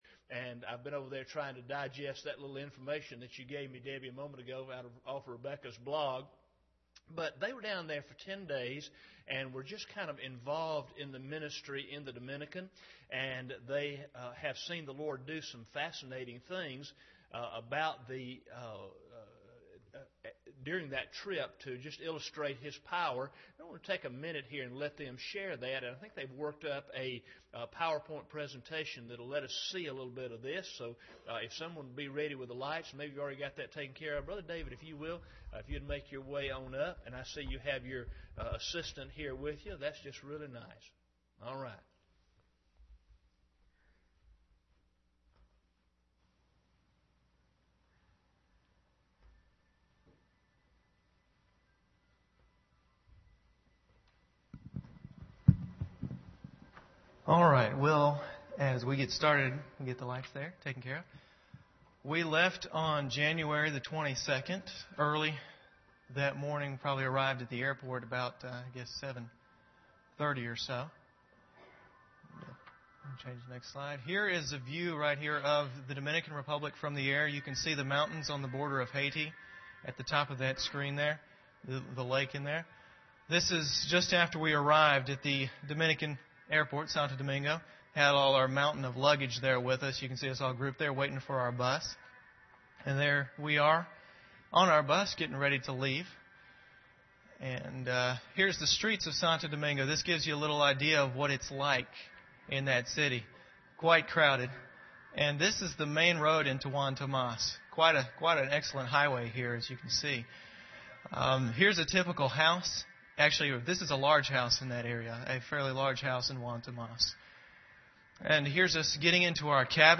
Bible Text: Matthew 8:20-34 | Preacher: CCBC Members | Series: General
Service Type: Sunday Evening